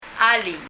Alii [áli:] 英） Hello 日） こんにちは alii には「ハロー！」